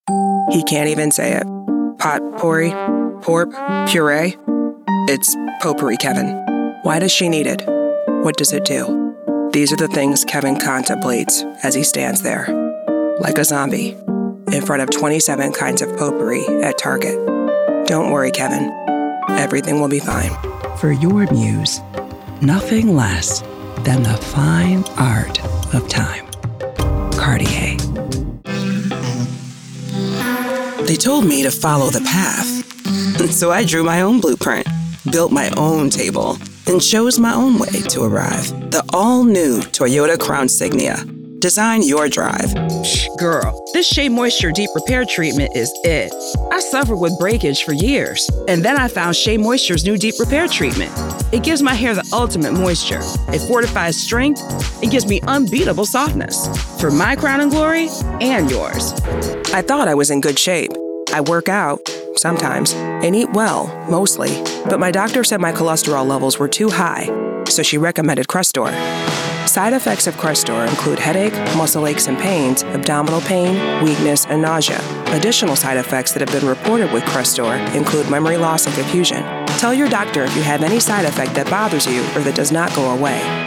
American female
voice actor
Commercial Demo
I use Source Connect Standard in my pro home studio (not shown here).